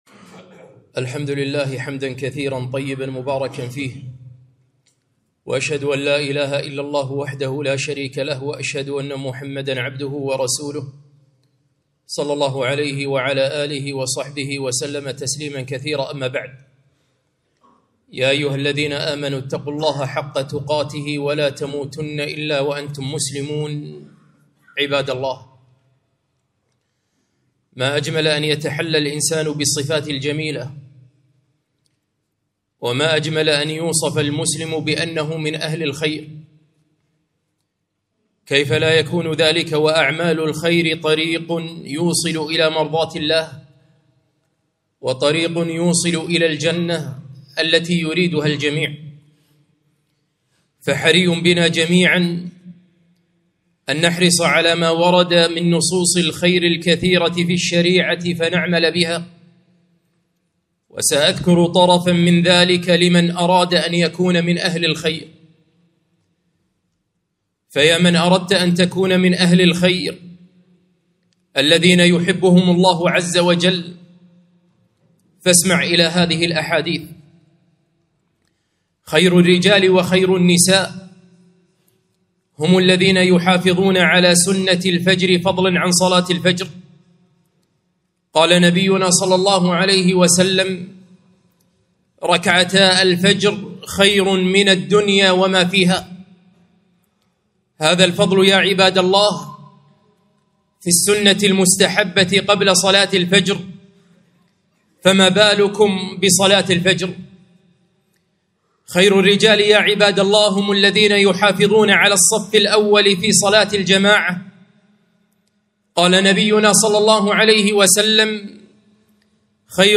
خطبة - كن من خيار الناس